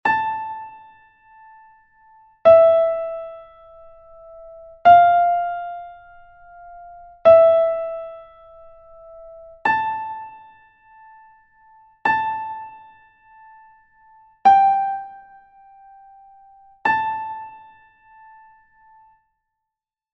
glockenspiel gif
Listen to the sound files to recognize the notes High D, High E, High F, High G, and High A . You will be given the first note of each exercise to make it easier.